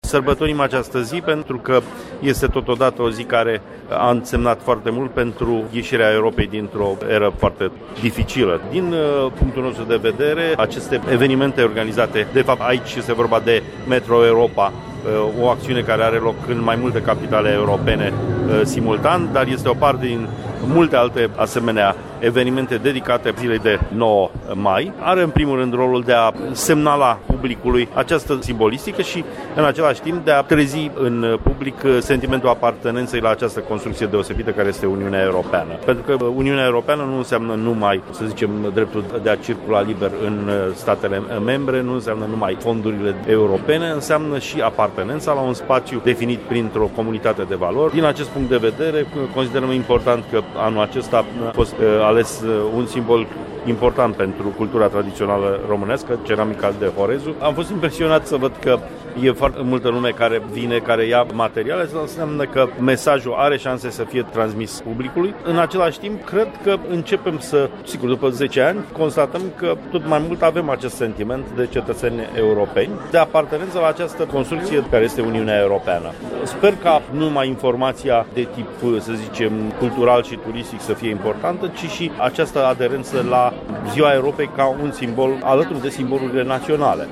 precum şi două declaraţii despre însemnătatea zilei de 9 Mai
Ministru secretar de stat în cadrul MAE